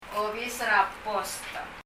obis er a post [ɔbis ɛr ə pɔst]
ネイティブの発音では、[ɔbisərə pɔst] と聞こえます。